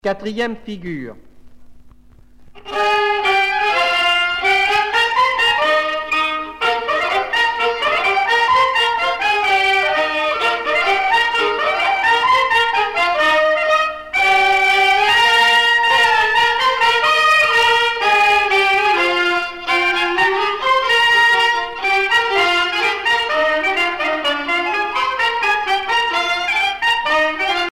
danse : quadrille
Pièce musicale éditée